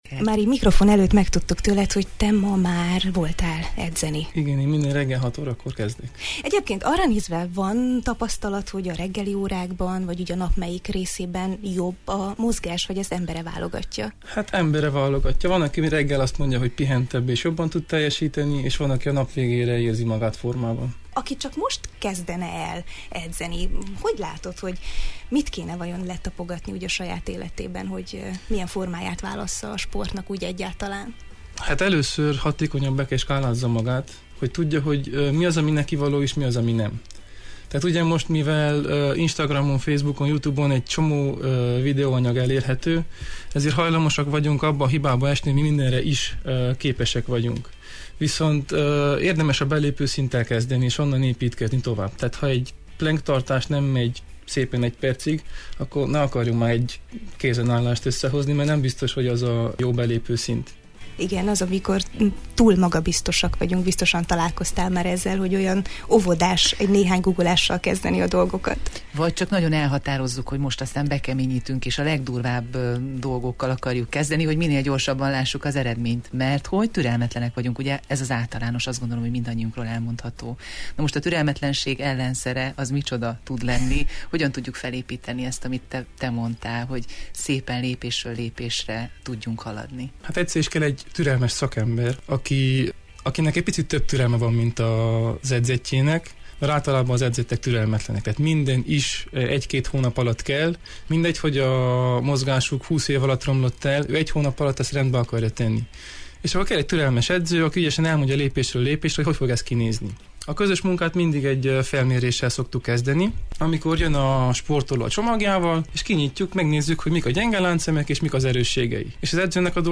A Jó reggelt, Erdély!-ben a mozgáshoz való viszonyunkról beszélgettünk